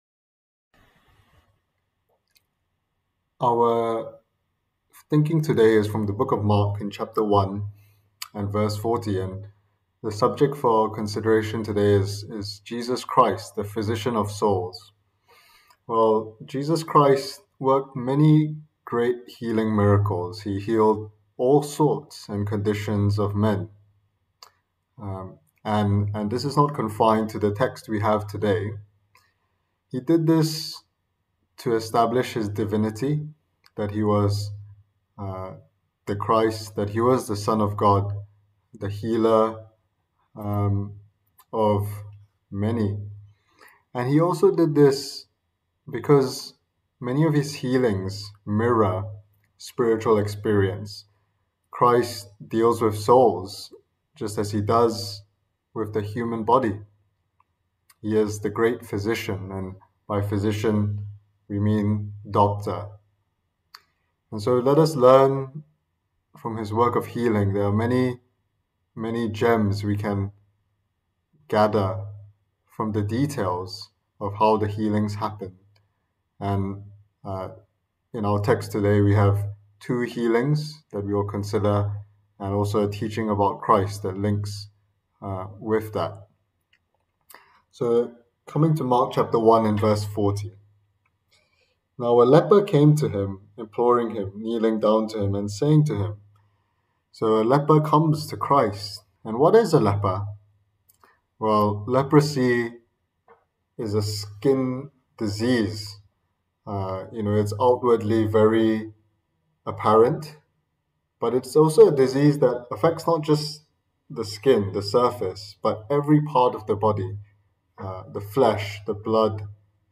delivered online in the Evening Service